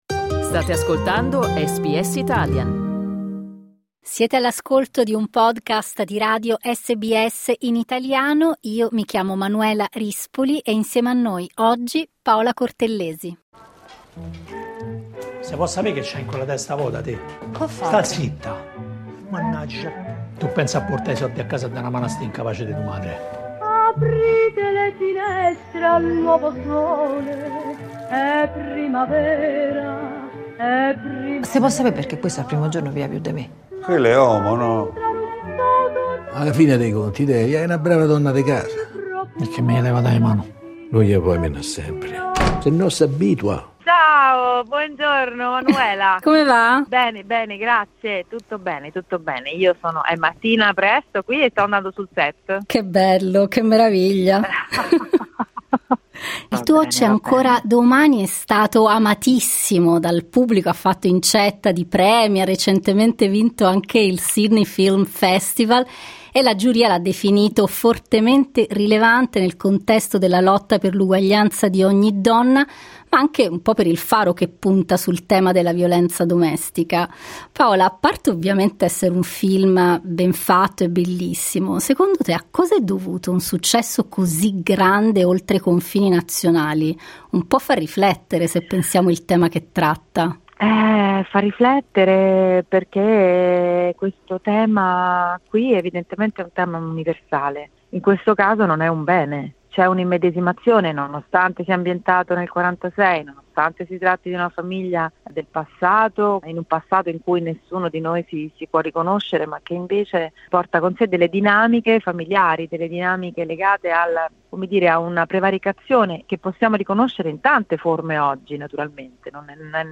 Clicca sul tasto "play" in alto per ascoltare l'intervista completa a Paola Cortellesi La giuria del Sydney Film Festival , ha ritenuto il lungometraggio C'è ancora domani fortemente rilevante nel contesto della lotta per l'uguaglianza di ogni donna.